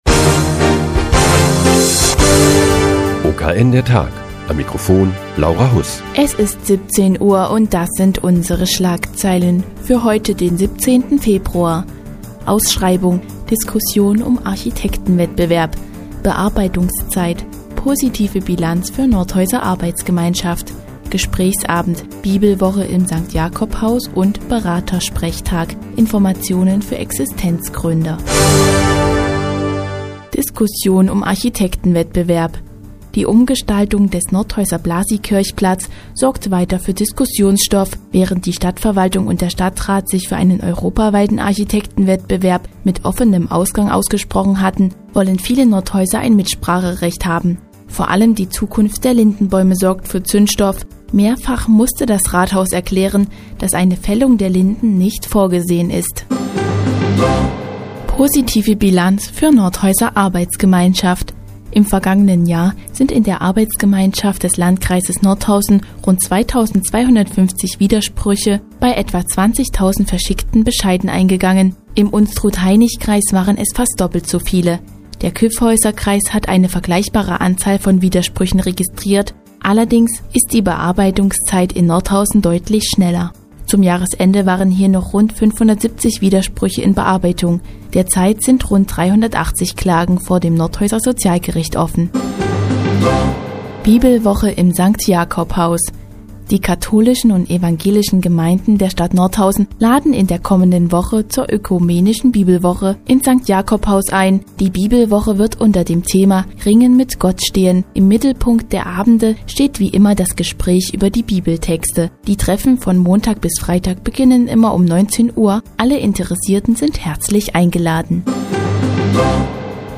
Die tägliche Nachrichtensendung des OKN ist nun auch in der nnz zu hören. Heute geht es um den Architektenwettbewerb für die Umgestaltung des Blasiikirchenplatzes und die Ökumenische Bibelwoche im Sankt Jakobhaus.